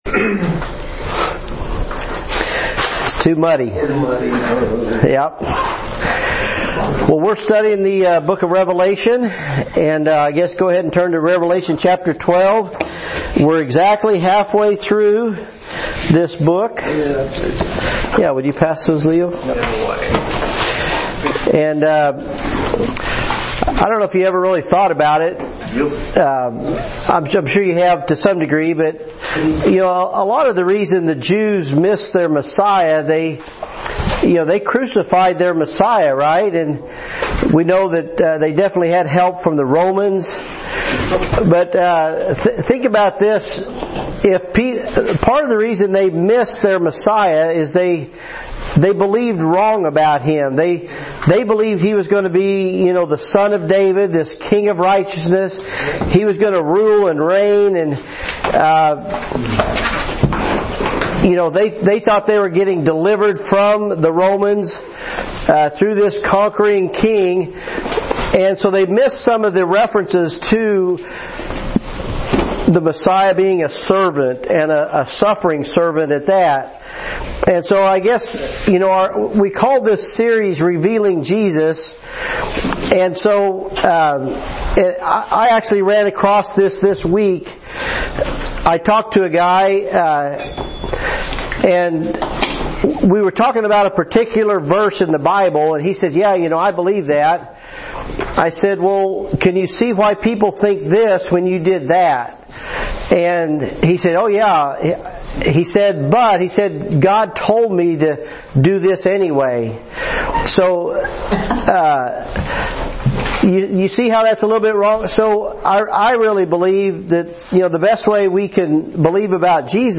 Audio was stopped a little early. This is not the full Lesson.